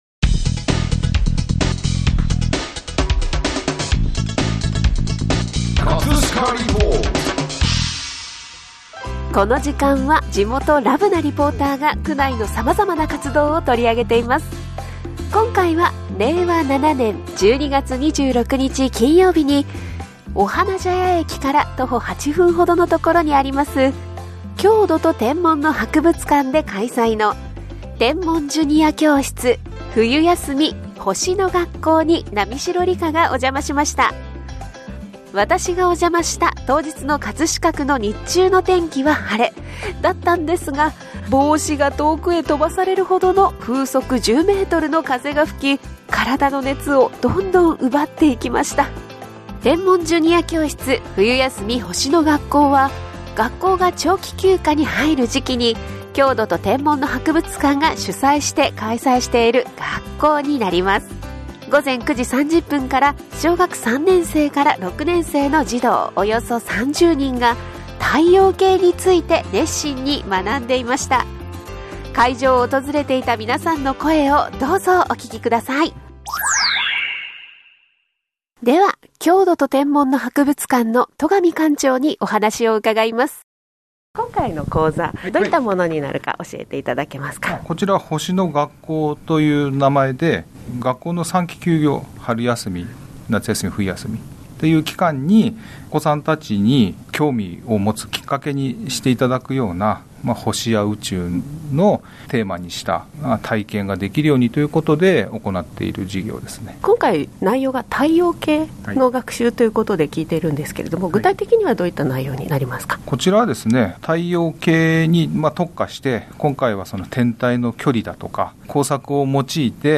午前9：30から、小学3年生から６年生の児童約30人が、 太陽系 について熱心に学んでいました。 会場を訪れていた皆さんの声をどうぞお聴きください。